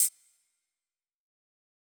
[Sweden HiHat].wav